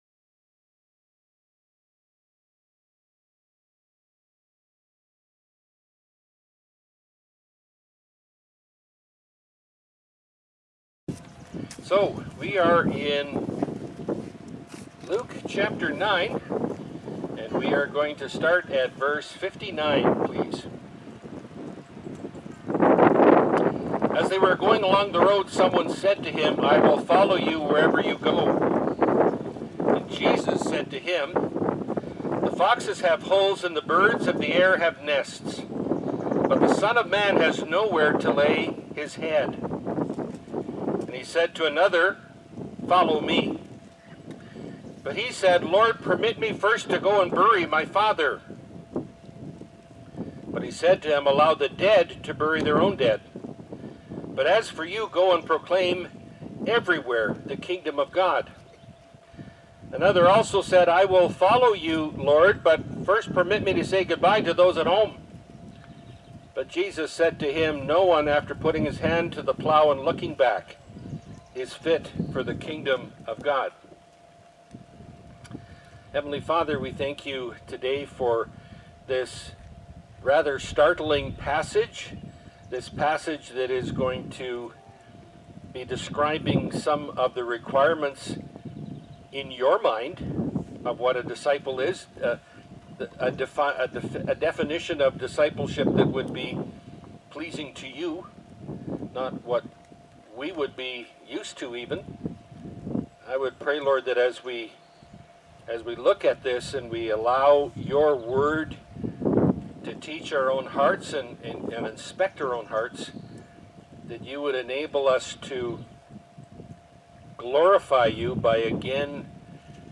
Category: Pulpit Sermons